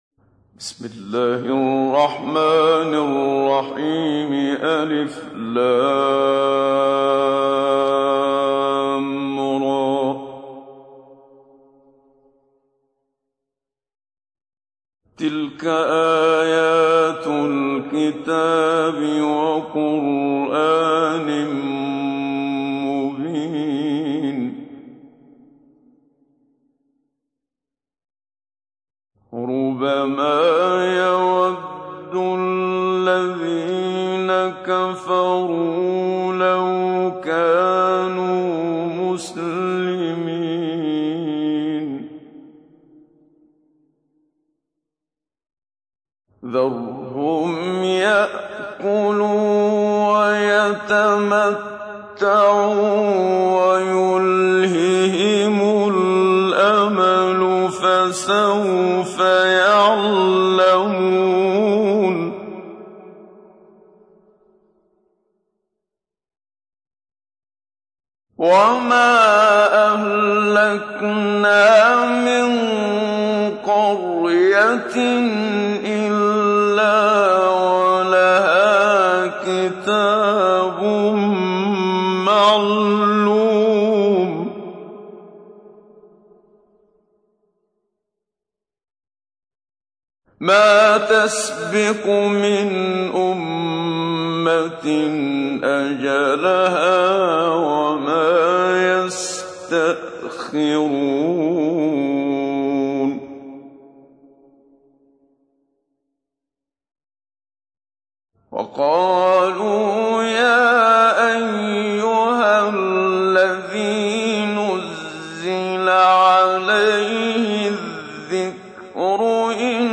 تحميل : 15. سورة الحجر / القارئ محمد صديق المنشاوي / القرآن الكريم / موقع يا حسين